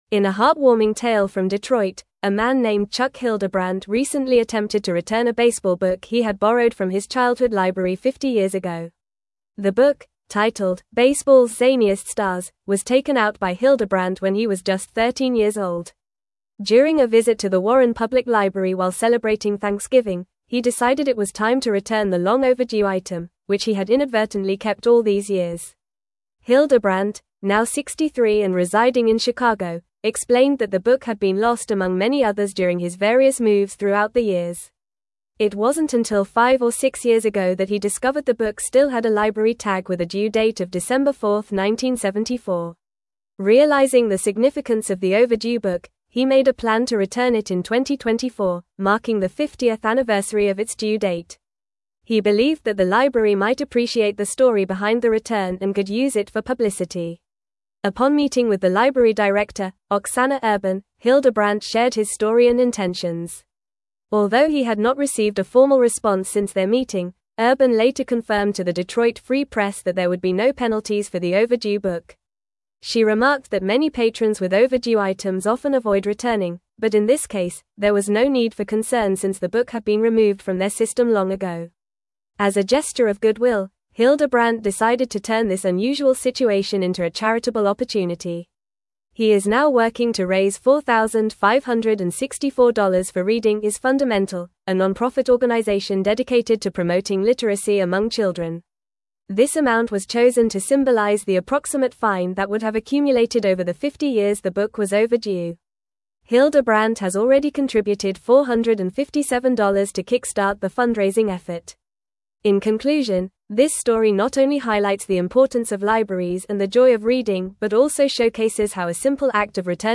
Fast
English-Newsroom-Advanced-FAST-Reading-Man-Returns-Overdue-Library-Book-After-50-Years.mp3